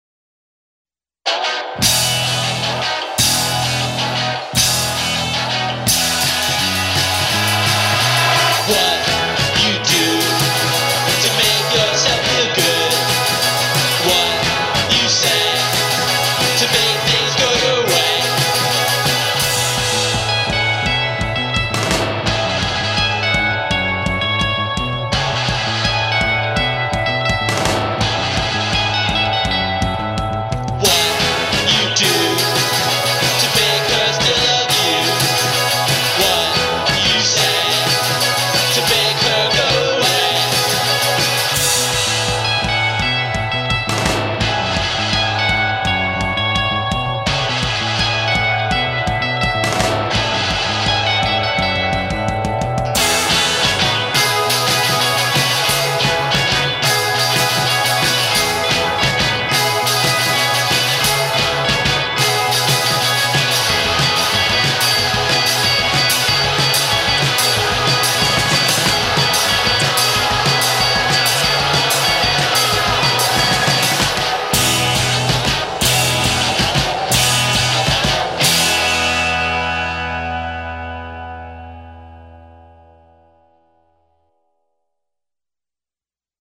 indie rockers
pop quartet